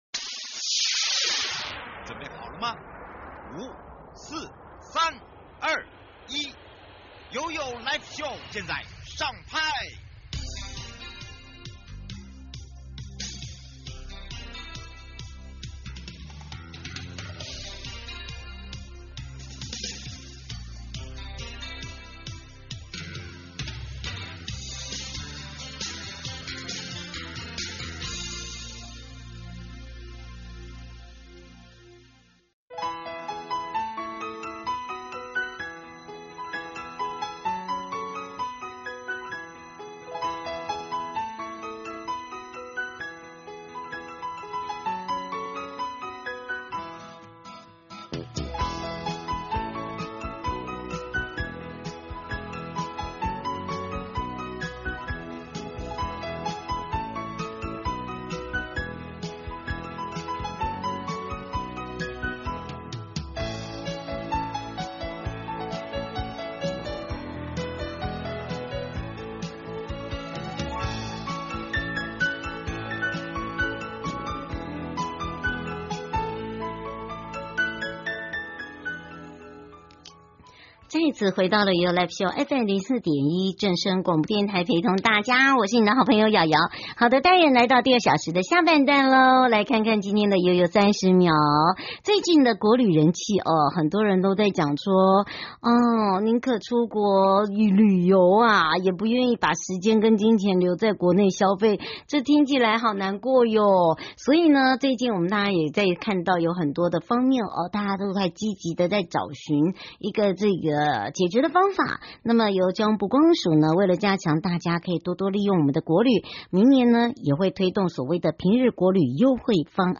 受訪者